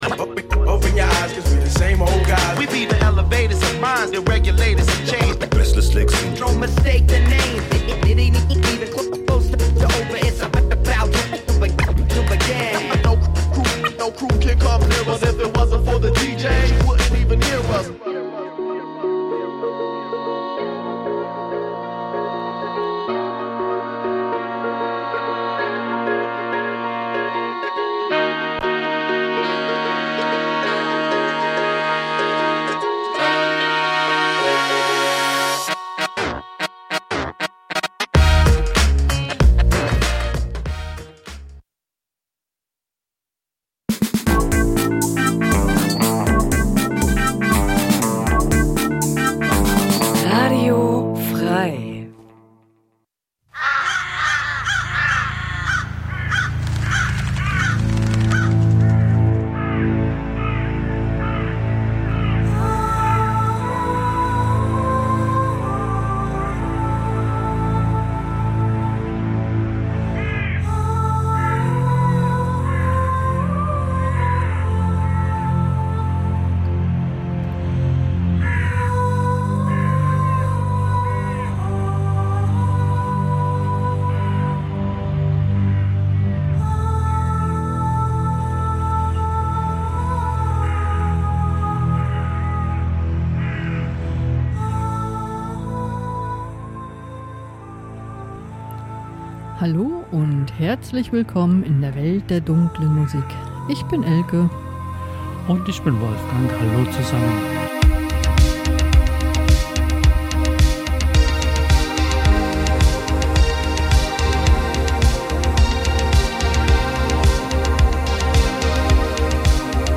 Musiksendung